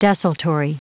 Prononciation du mot desultory en anglais (fichier audio)
Prononciation du mot : desultory